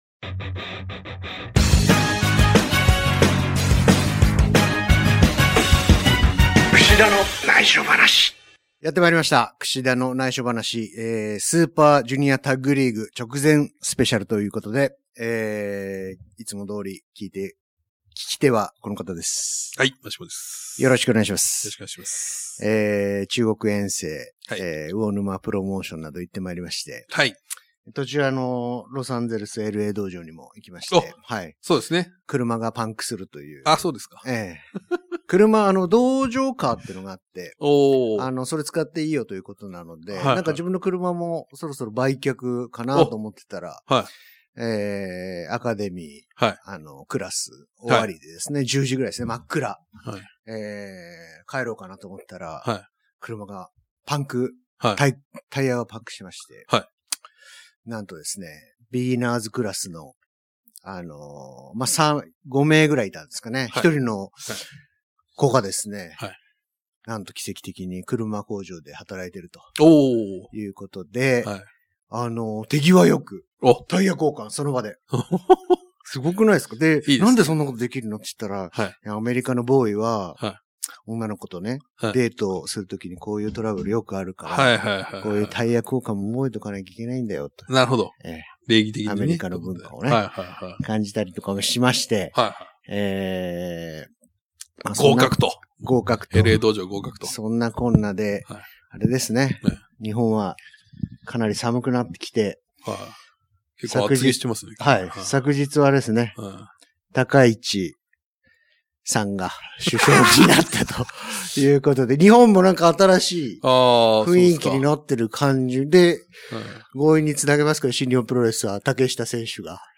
Podcast『KUSHIDAのナイショ話』#51「『SUPER Jr.TAG』で緊急収録！